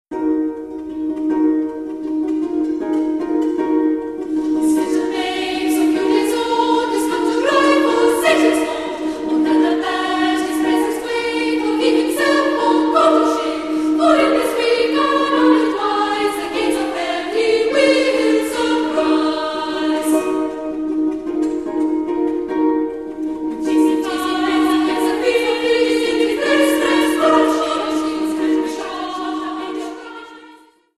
Works by the great trilogy of choral composers, Bach, Byrd and Britten sung by the magnificent Choir of Ormond College.
Indicative of the choir's breadth of repertoire and talent in performing a vast range of musical styles. Features Bach's motet 'Lobet den Herrn alle Heiden', Byrd's 'Mass for Four Voices' and Britten's delightful 'Five Flower Songs' and 'A Ceremony of Carols'.